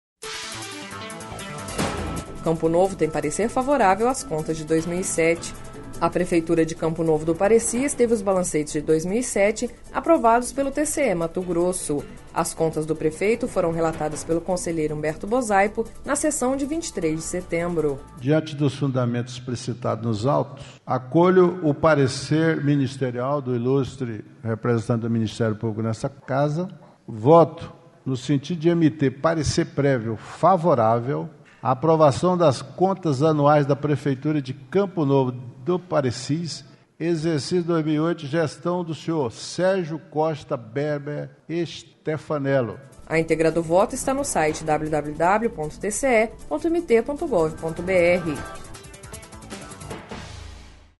Sonora: Humberto Bosaipo – conselheiro do TCE-MT